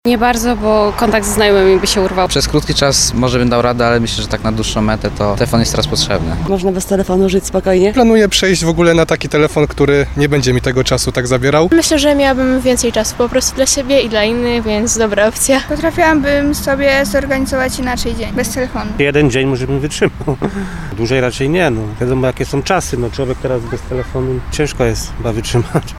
Czy można w tych czasach żyć bez smartfona? Zapytaliśmy o to Sądeczan.
15telefon_sonda.mp3